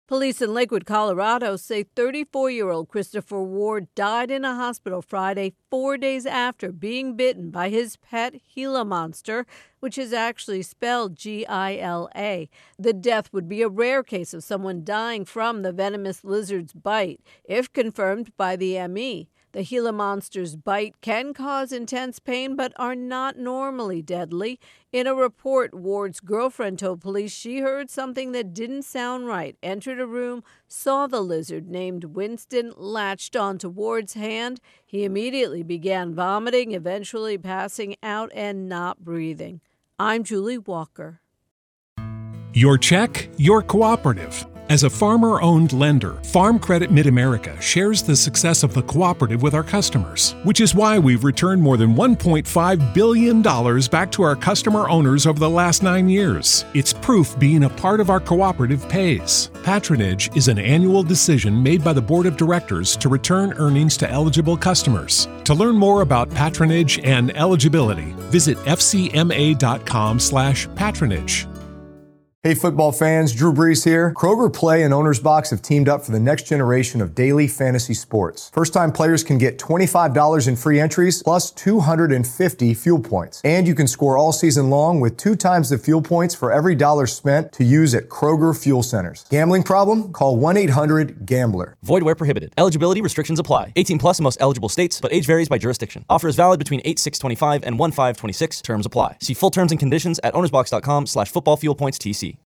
((Gila is pronounced HEE'-luh))